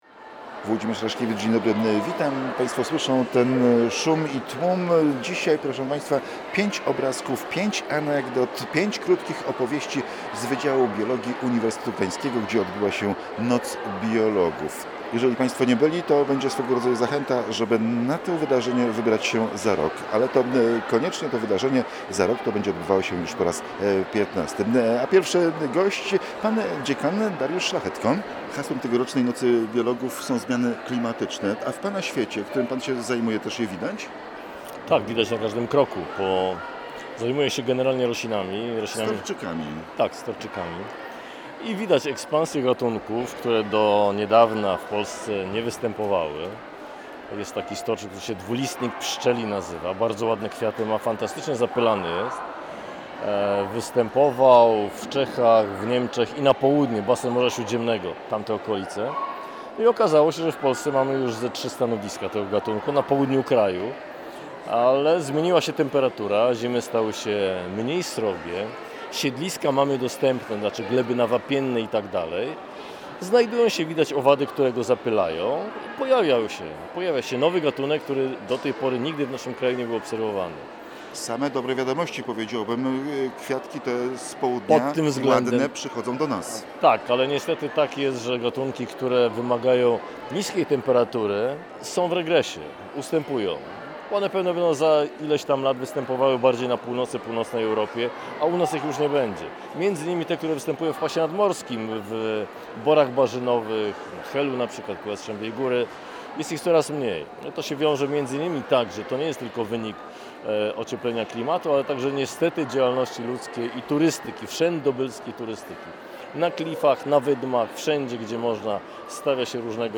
Pod tym tytułowym hasłem odbyła się XIV Noc Biologów.
Nasz reporter pytał o zauważalne skutki zmian klimatycznych w świecie roślin i zwierząt.